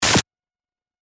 アタック 001
シンプル 電撃ビリッ